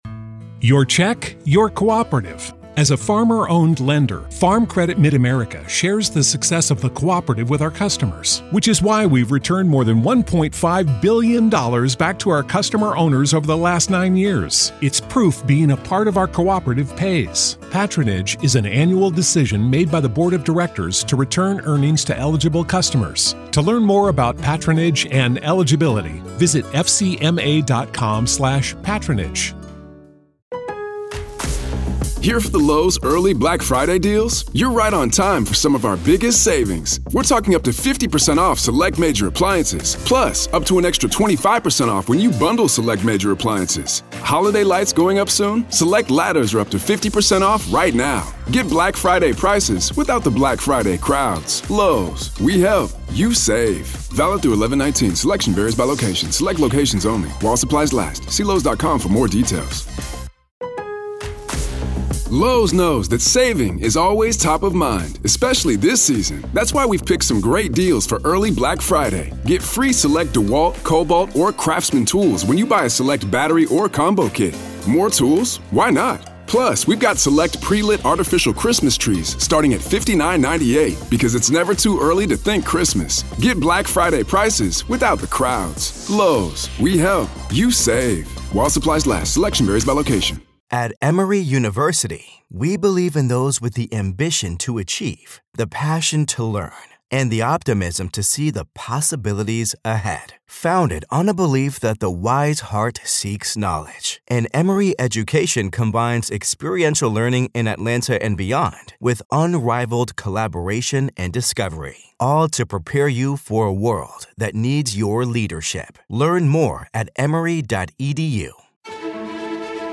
RAW COURT AUDIO: Judge Halts Karen Read Pretrial Hearing After ‘Grave Concern’ Over New Information PART 2